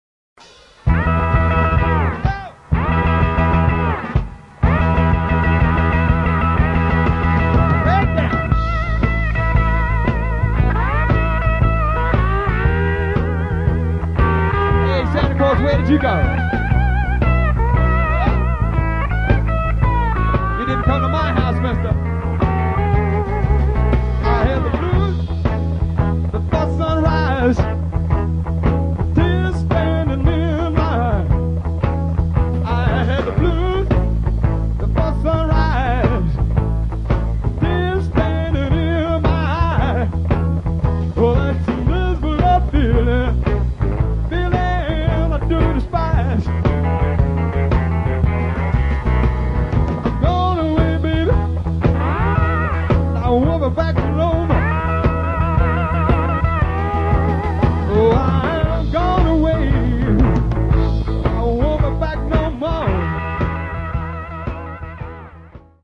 Classic live recordings from our vast back catalogue.